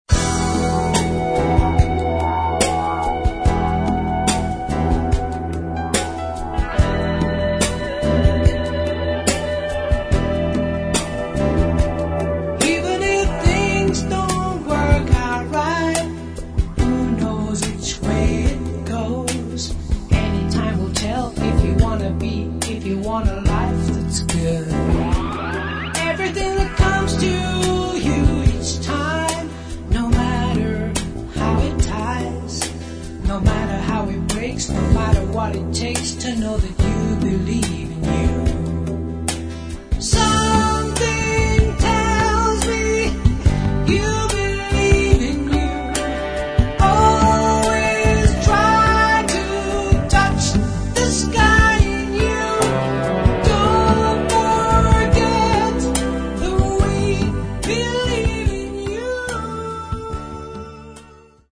Рок
На альбоме два вокала
В любом случае, качество записи на высоте.